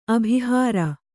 ♪ abhihāra